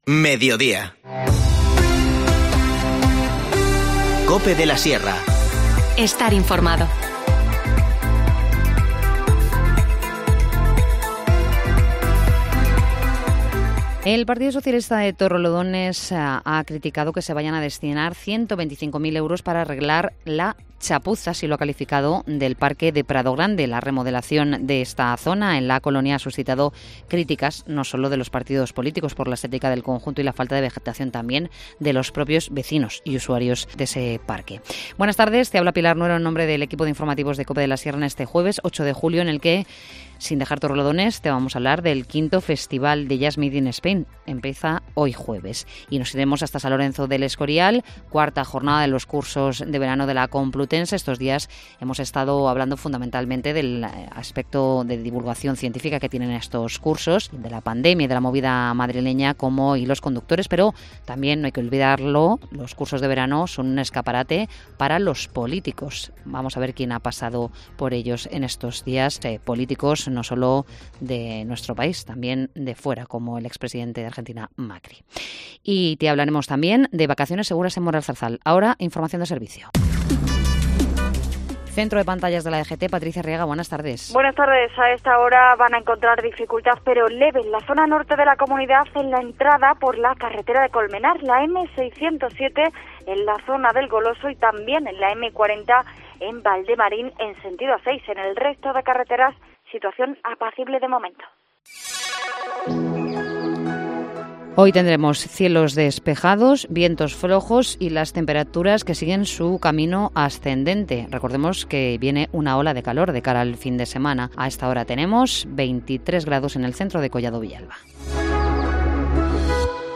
Informativo Mediodía 8 julio